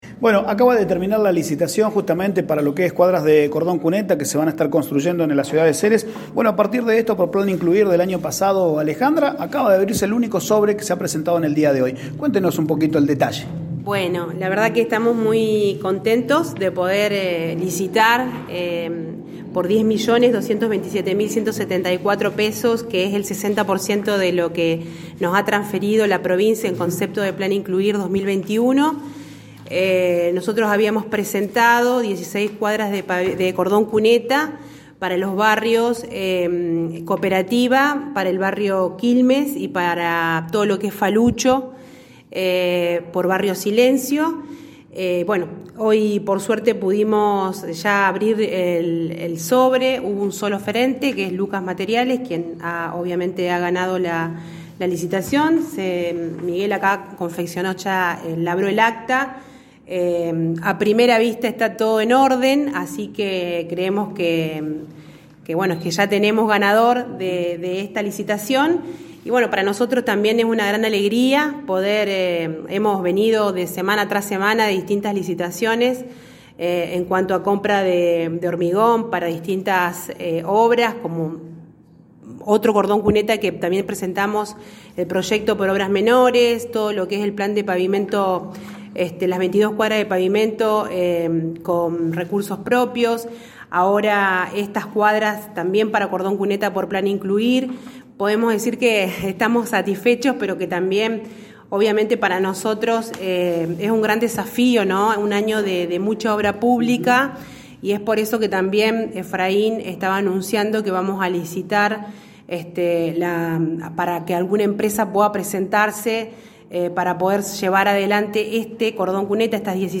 Ni bien termino la Licitación Pública para la compra de materiales para n16 cuadras de cordon cuneta pudimos entrevistar a la Intendente Alejandra Dupouy, al Secretario de Obras Publicas Efraín Rojas y al Secretario de Hacienda Miguel Andrada.
Dupouy, Rojas y Andrada hicieron declaraciones sobre la licitación pública de cordón cuneta, la obra de pavimento y la compra de una retroexcavadora.